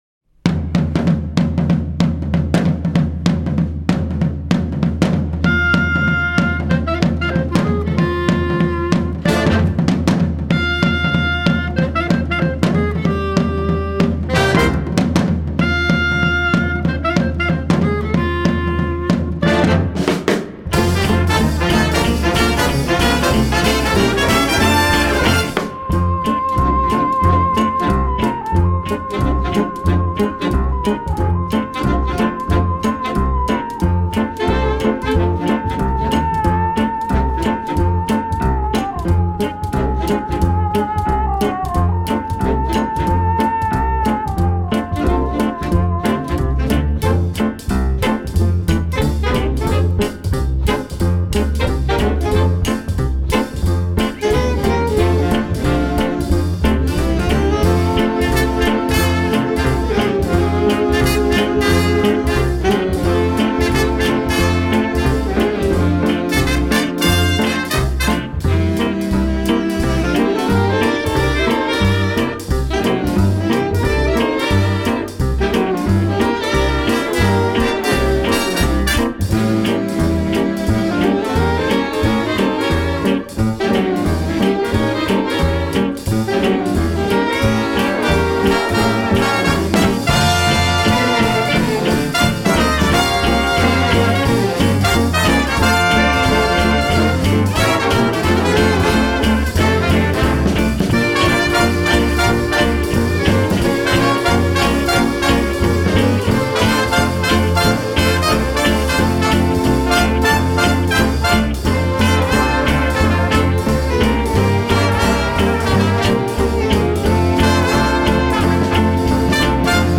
バンドレコーディング向けマルチトラックのミックスサービスとなります。
マルチトラックサンプルミックス